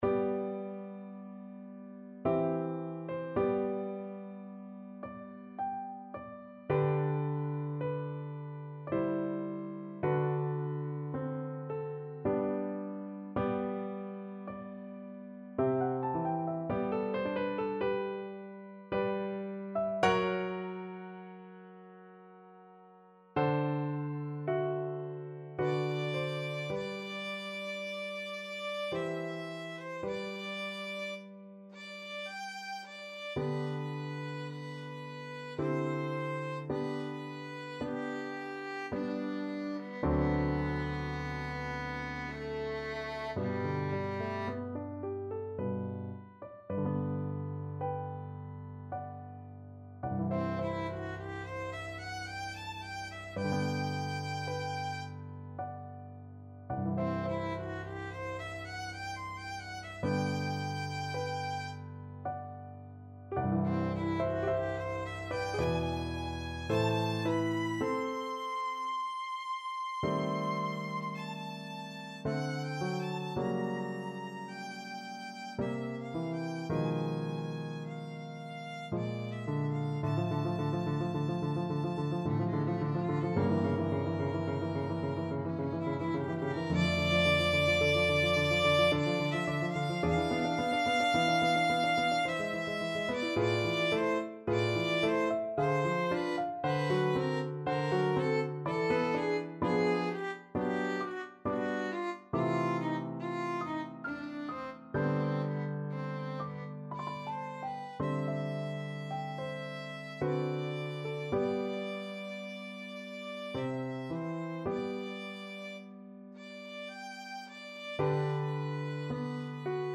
3/4 (View more 3/4 Music)
Adagio ma non troppo =108
Classical (View more Classical Violin Music)